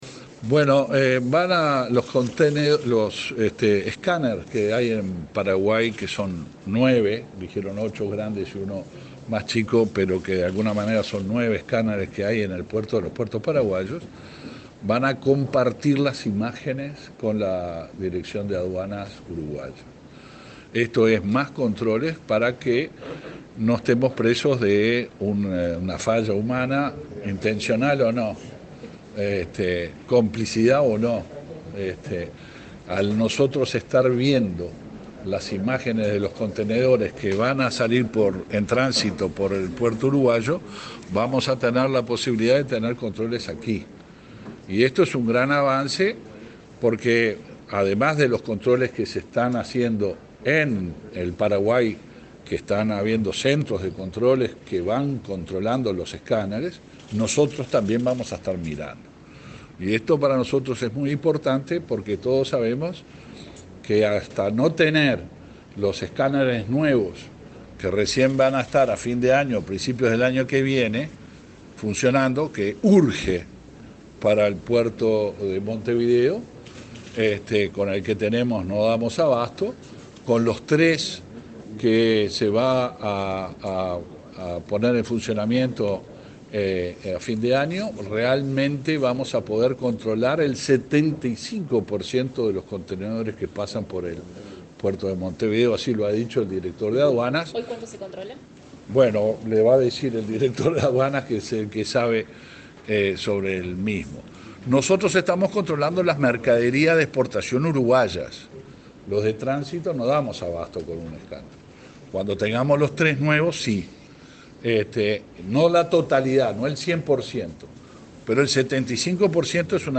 Declaraciones del ministro del Interior, Luis Alberto Heber
El ministro del Interior, Luis Alberto Heber, participó, este jueves 20 en la Embajada de Paraguay en Uruguay, de la firma de un convenio de